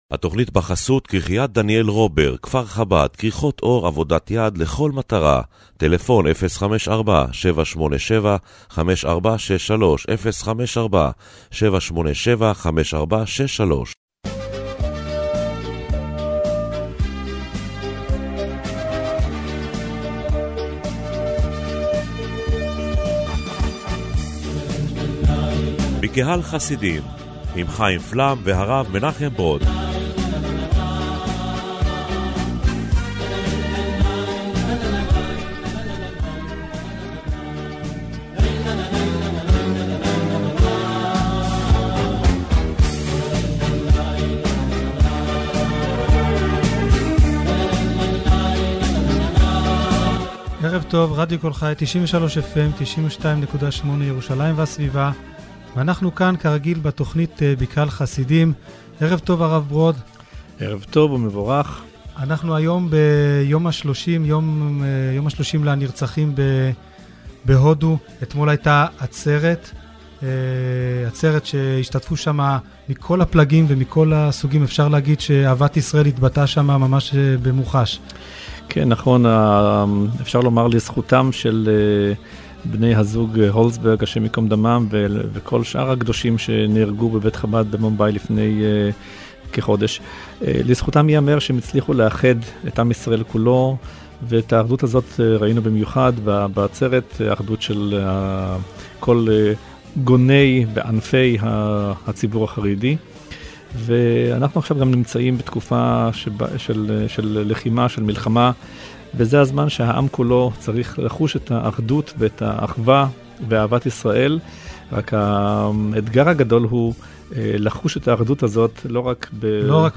מזה תקופה משודרת תוכנית רדיו שבועית, העוסקת בחסידות לגווניה, שעלתה לרדיו קול חי, ובה שולב שיעור שבועי בספר התניא.